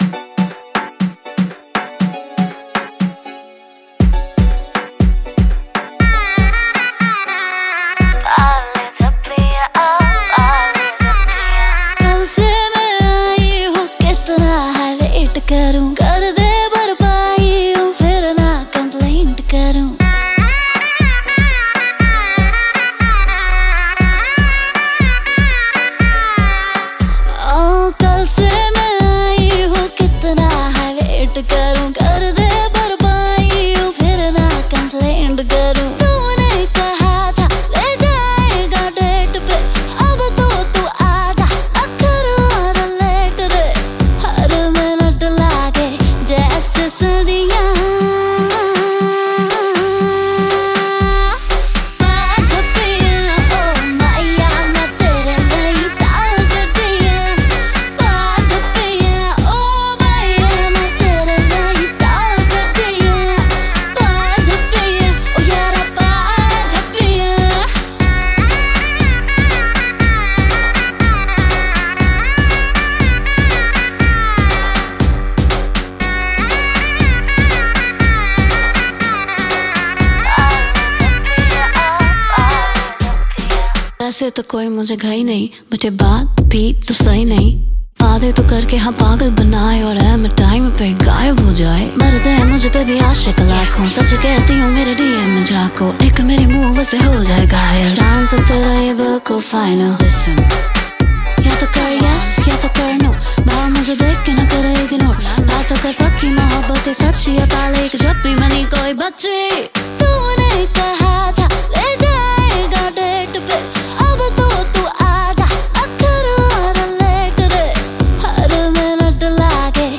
Single Indian Pop